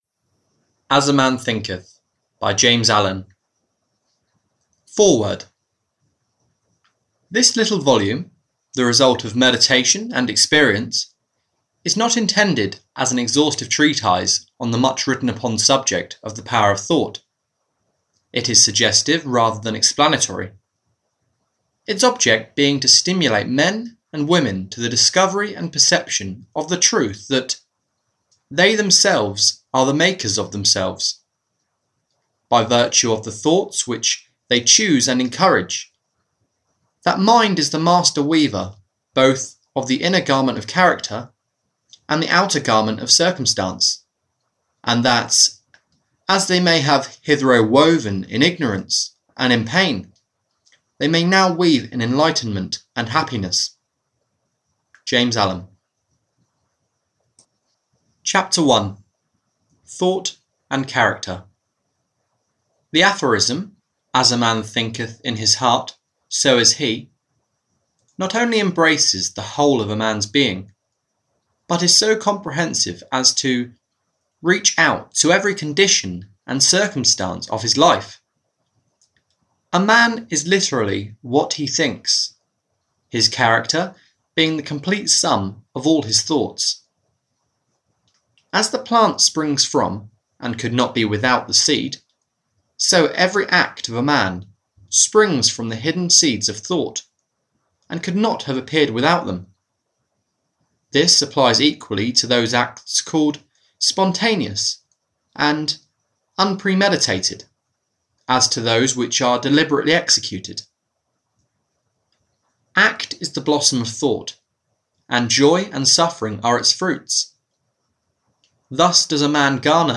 As A Man Thinketh (EN) audiokniha
Ukázka z knihy